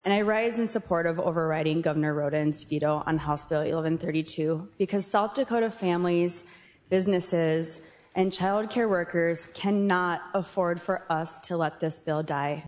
House Minority Leader Erin Healy, D-Sioux Falls, urged colleagues to override the veto.